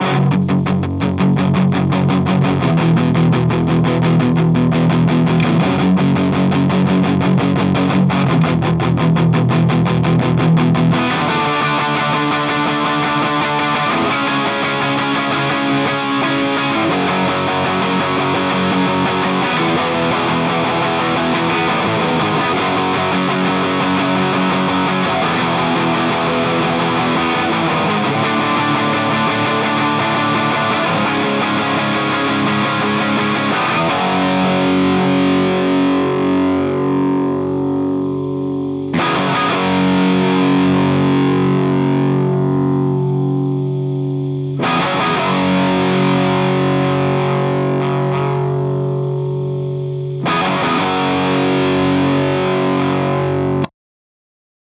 Bluecat is the left channel. LePou is the right
Classic drive
Cab sims are all NaDir.
The big thing I'm noticing is that the Bluecat sims are more "squashed" overall. There is less dynamic response to the incoming guitar tone, and the noise floor is a lot higher.